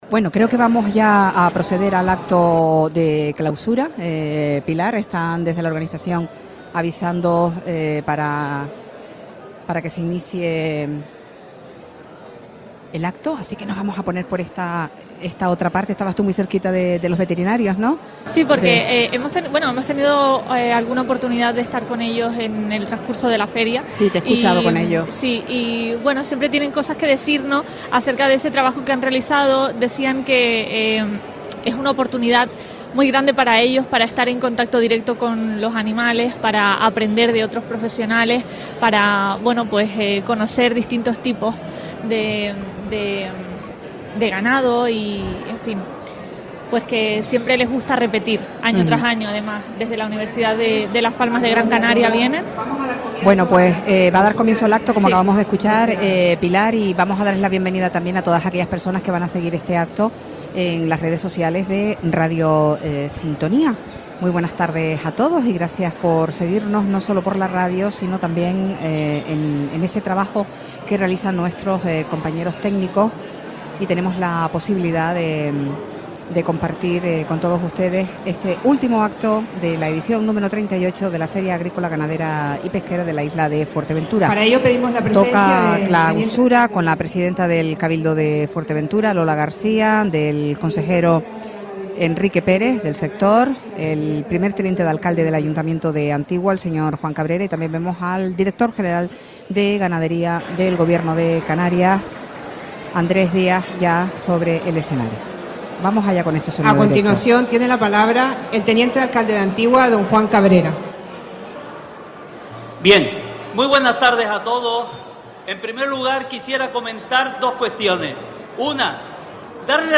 Clausura de Feaga 2026 desde la Granja Experimental de Pozo Negro - Radio Sintonía
Entrevistas